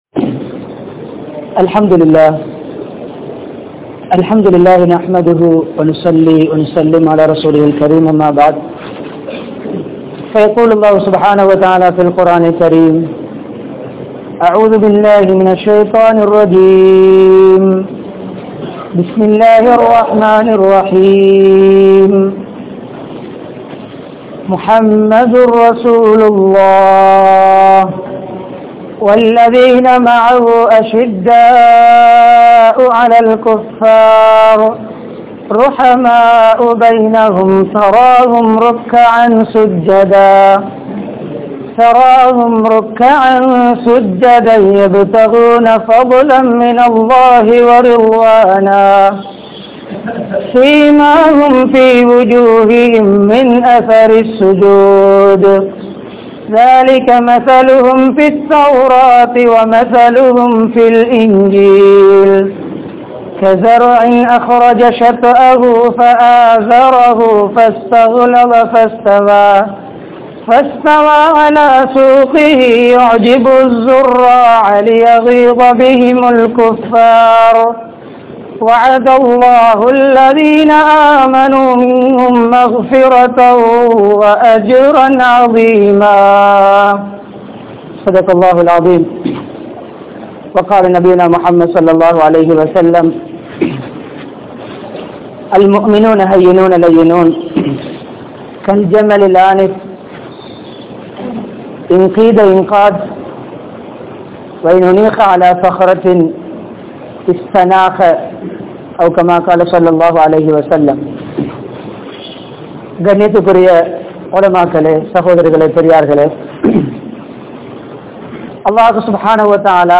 Dhauwath Pani Seiungal (தஃவத் பணி செய்யுங்கள்) | Audio Bayans | All Ceylon Muslim Youth Community | Addalaichenai